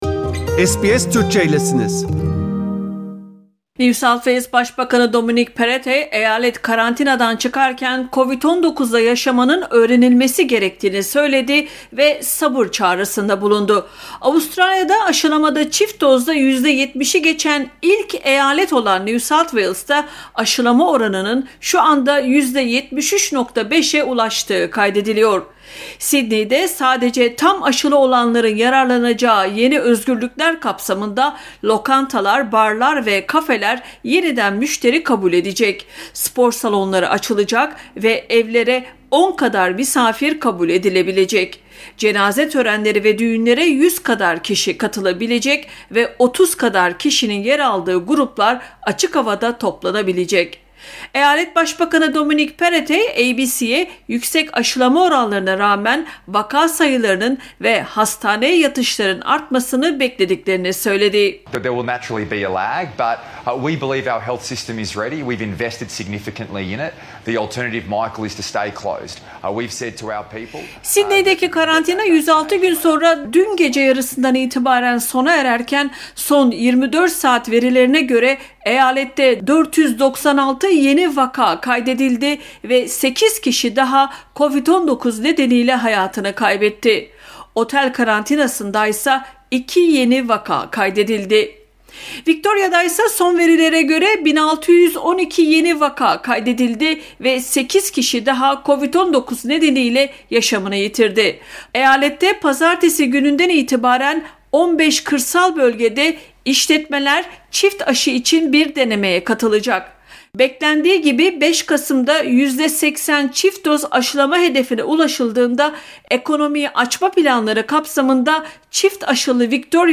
SBS Türkçe Haberler 11 Ekim